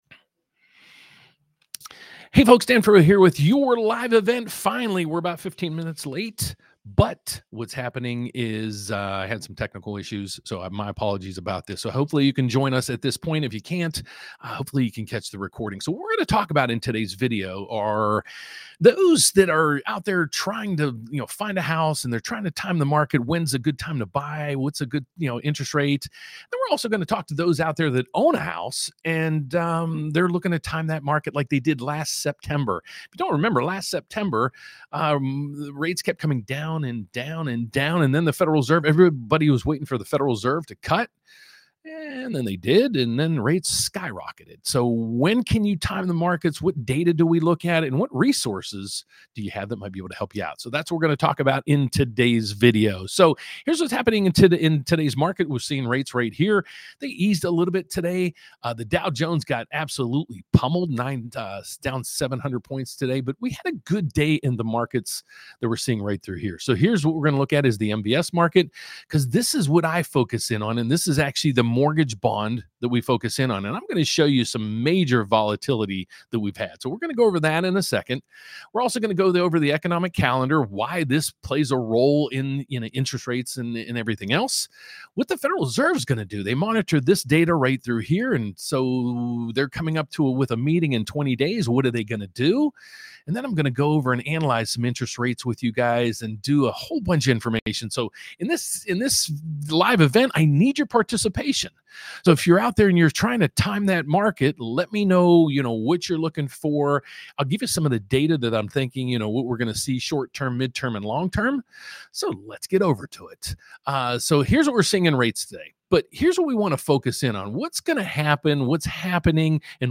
Should You LOCK Your Mortgage Rate Now? | Live Q&A on Rate Timing & Strategy